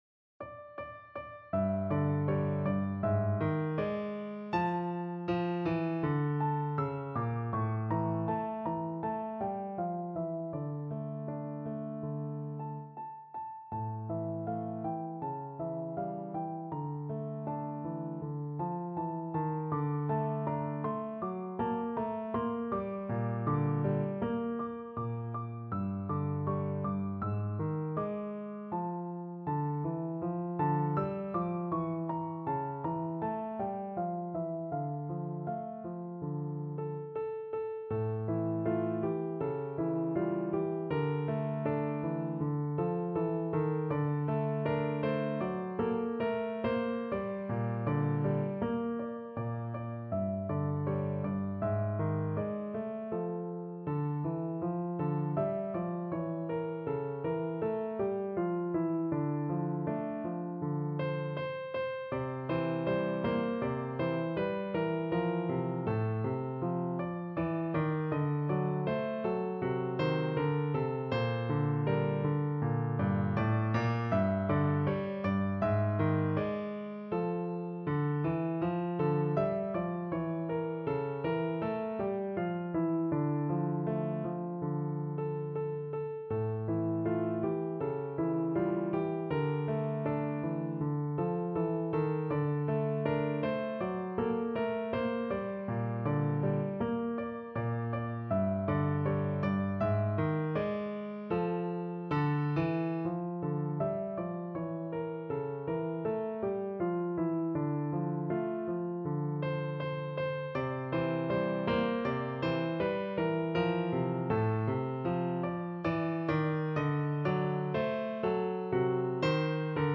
Free Sheet music for Piano
No parts available for this pieces as it is for solo piano.
2/4 (View more 2/4 Music)
F major (Sounding Pitch) (View more F major Music for Piano )
Andante
World (View more World Piano Music)
Brazilian